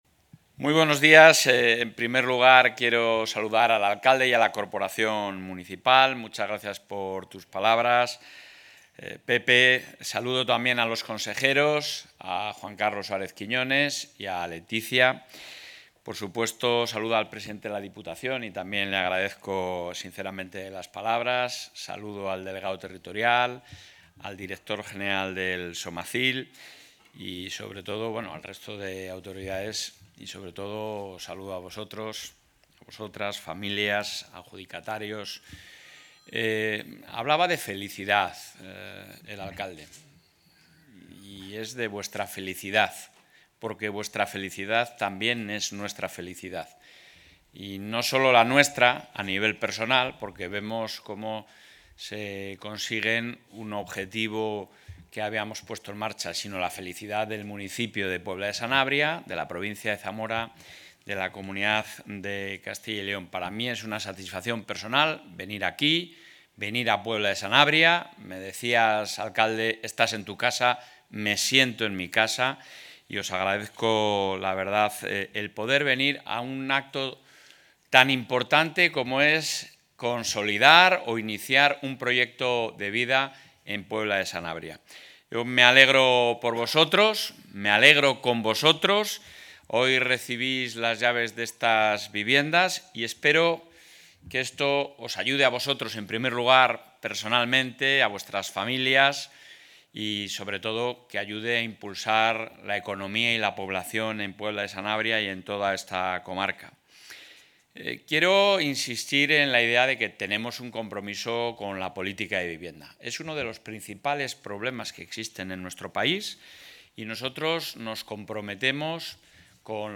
El presidente de la Junta de Castilla y León, Alfonso Fernández Mañueco, ha participado hoy en el acto de entrega de 14...
Intervención del presidente de la Junta.